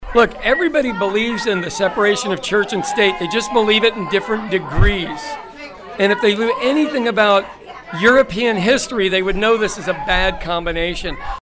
Tensions rose, as crowds gathered ahead of a board meeting at the Oklahoma State Board of Education. Protestors from both sides were vocal and bibles in the classroom were a loud rally point before the session started.